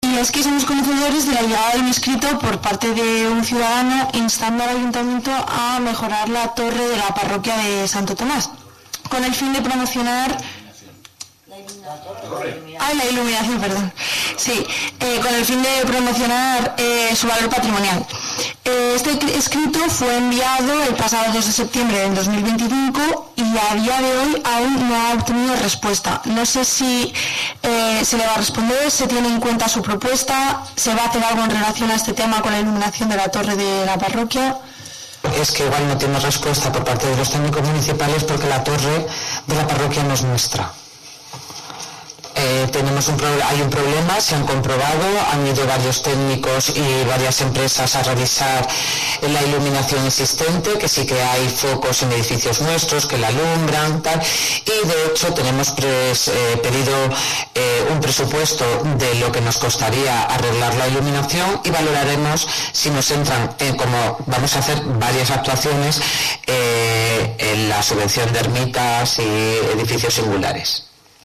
La alcaldesa de Haro Guadalupe Fernández indicó en el último pleno municipal que están valorando cómo recuperar la iluminación de la torre de la parroquia.